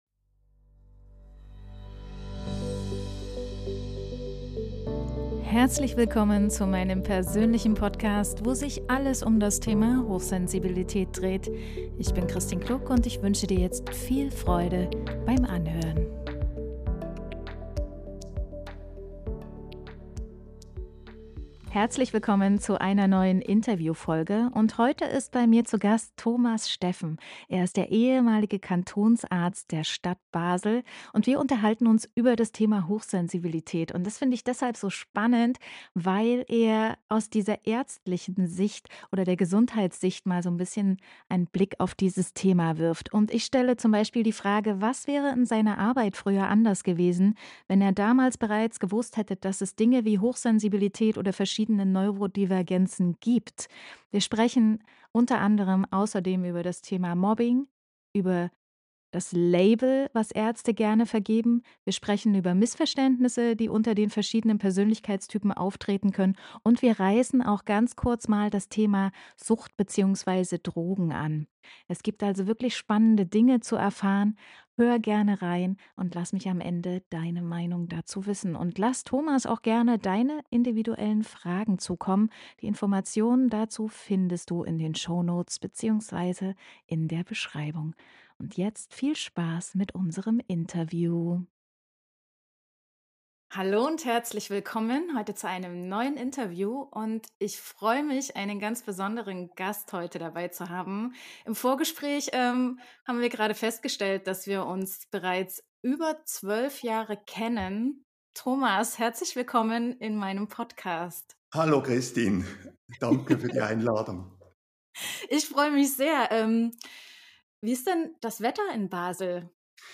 Beschreibung vor 1 Jahr In meinem 3. Podcast-Interview habe ich den ehemaligen Kantonsarzt von Basel/Stadt zu Gast.